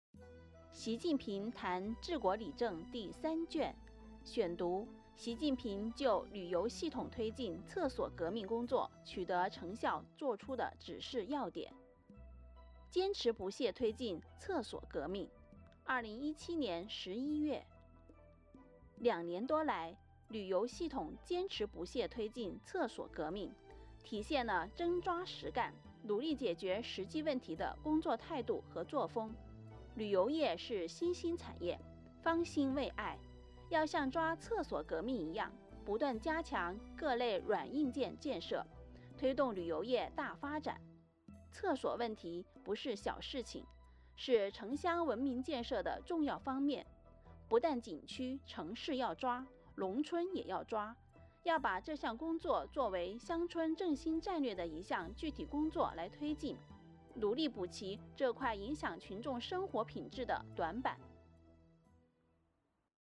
12.1、提高保障和改善民生水平：坚持不懈推进“厕所革命“（2017年11月）-朗读者